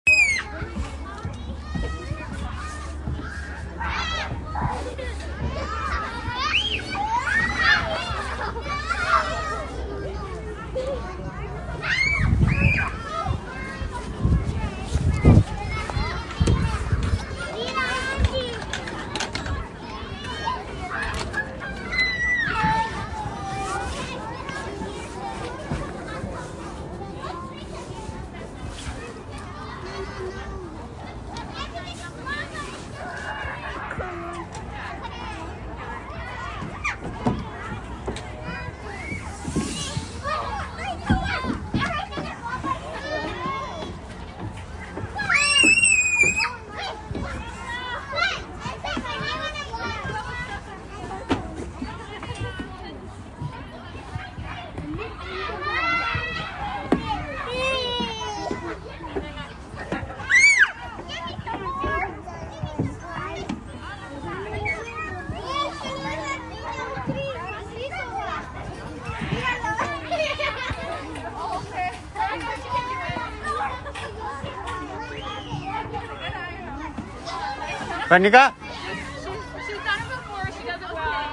Download Kids sound effect for free.
Kids